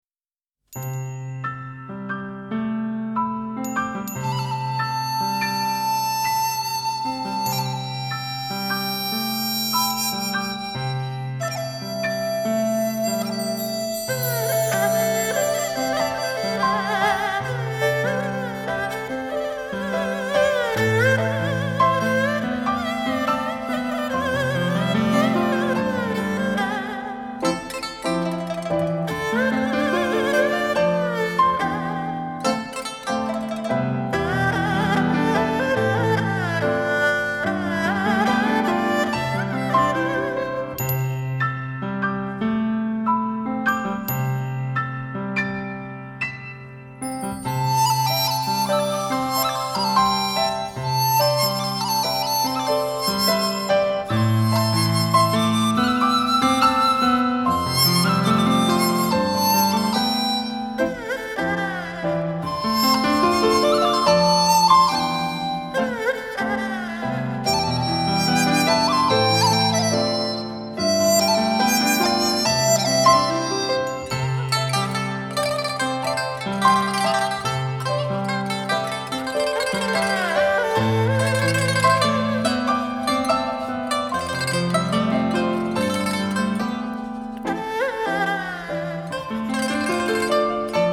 鋼琴超凡的獨奏表現力，帶給這些熟稔旋律金玉璀璨的外觀。
色彩繽紛的各色民樂器透過黑白琴鍵之間的交錯，豐富而絢麗的對話，令人目不暇接！